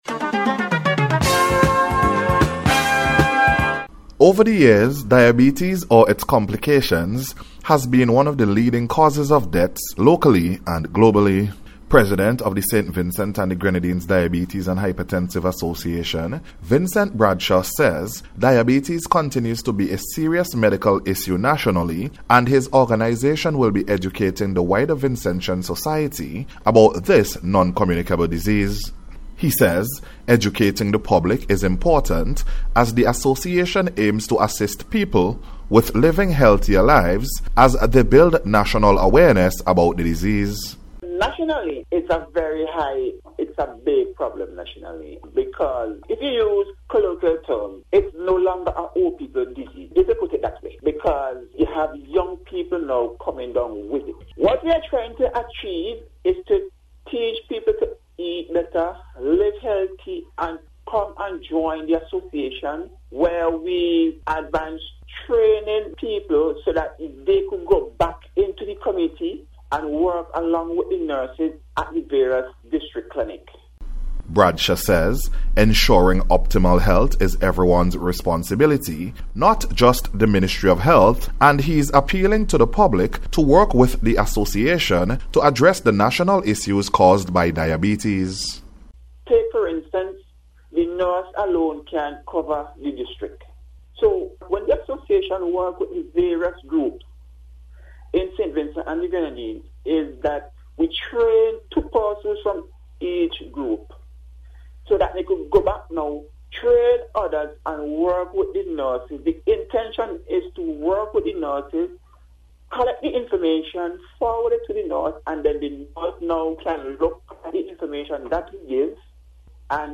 WORLD-DIABETES-DAY-REPORT.mp3